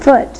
1 channel
foot.wav